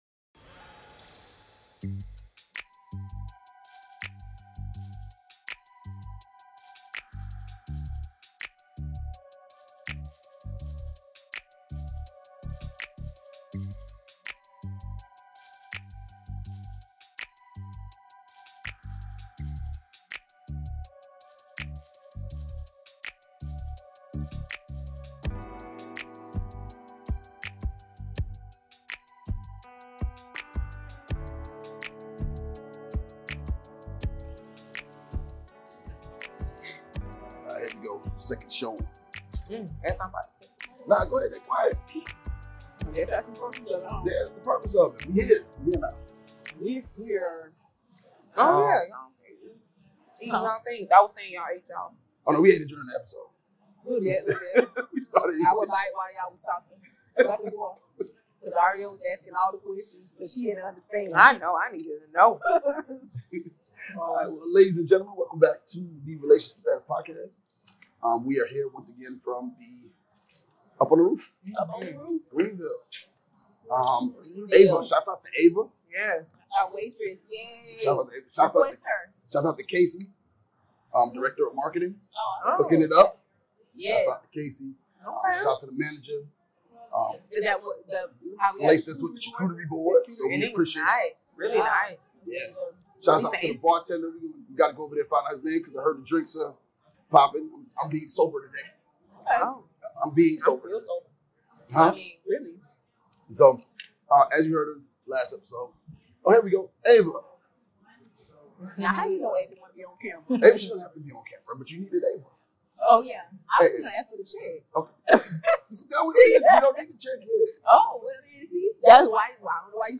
Recorded live at The Up On The Roof Restaurant — thank you for hosting us!
- PLEASE EXCUSE THE SOUND QUALITY -